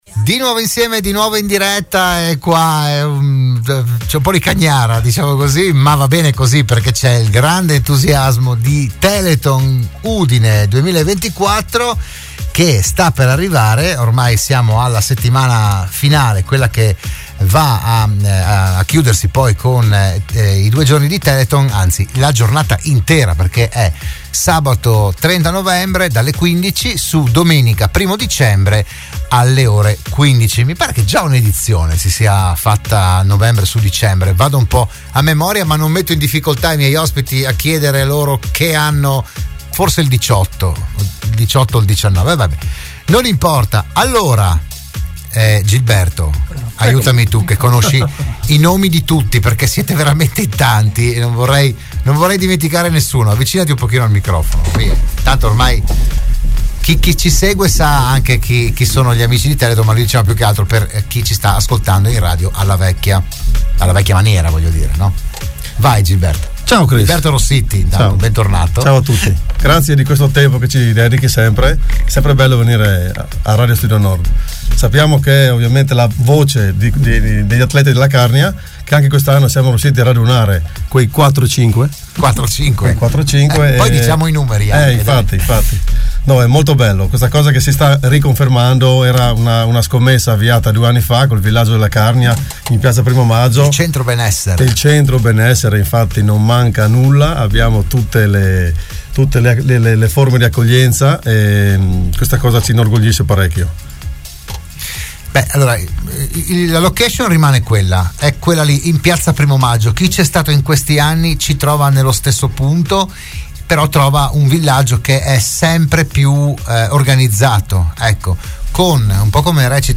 Ospiti a Radio Studio Nord